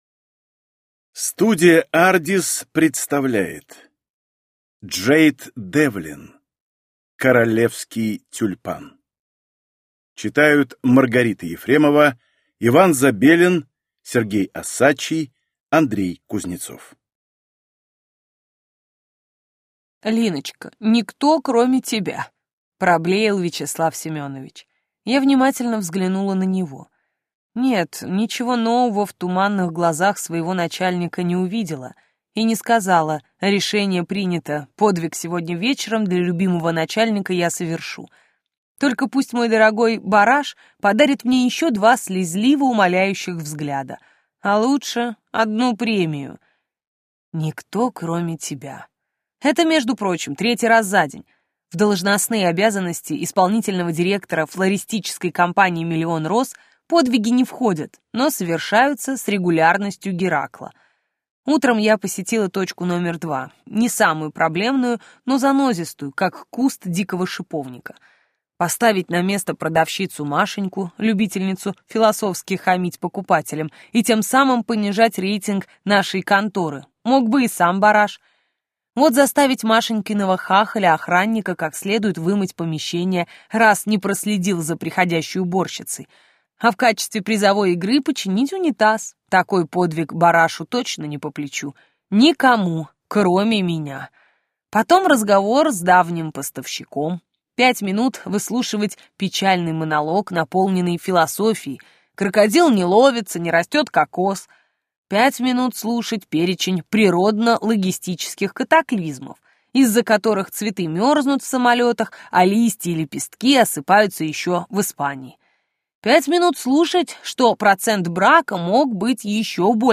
Леди из Фроингема (слушать аудиокнигу бесплатно) - автор Шарлотта Брандиш